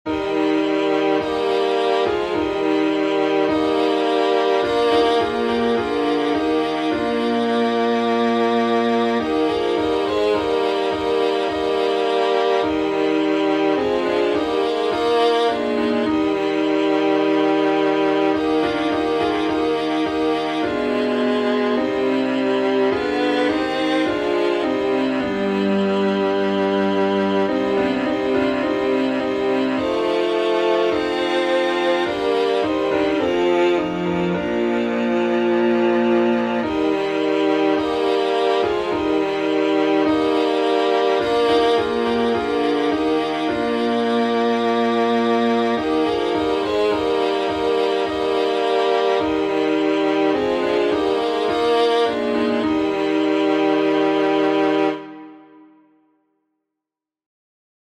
#5: Thine Be the Glory — alternate chording | Mobile Hymns
Key signature: E flat major (3 flats) Time signature: 4/4